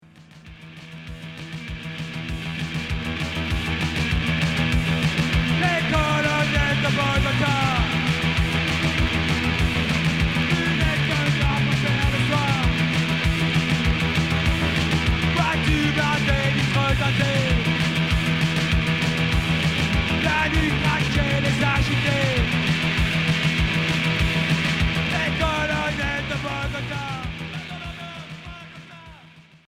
Punk industriel